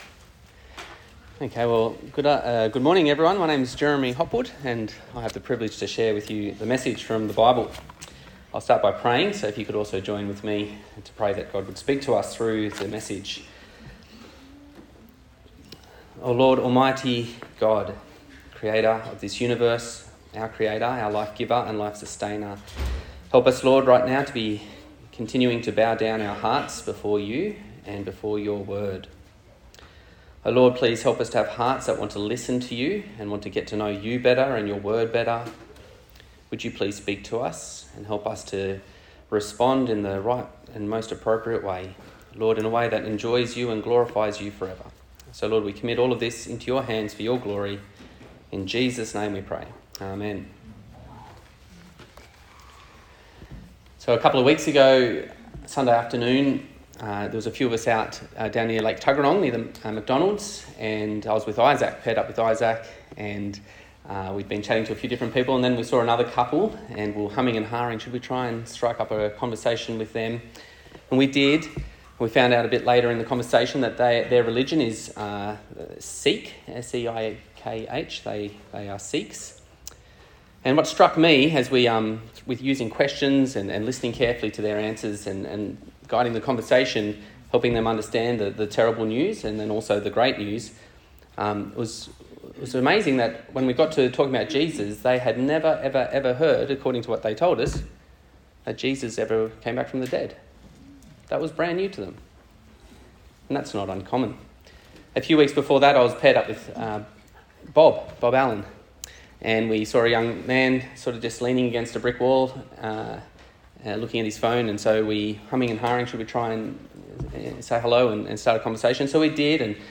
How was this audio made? Romans Passage: Romans 10:9-15 Service Type: Sunday Service